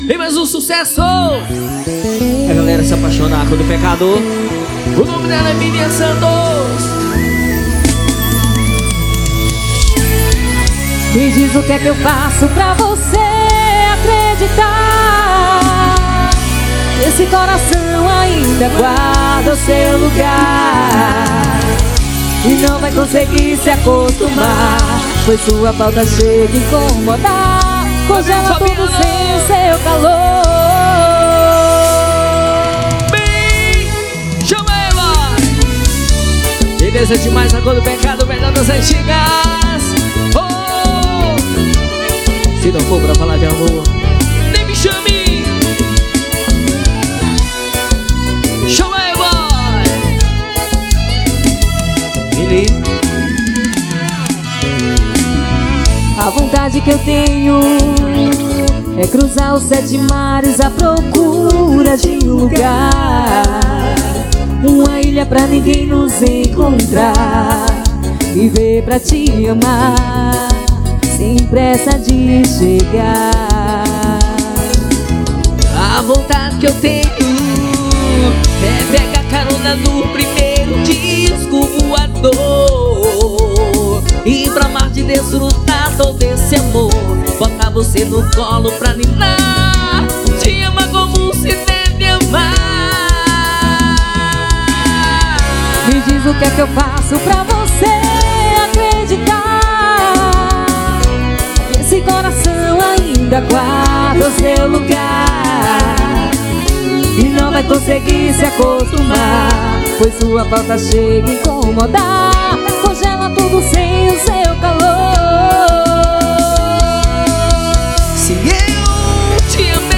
• Atração: A Cor do Pecado
• Estilo: Forró – do clássico ao contemporâneo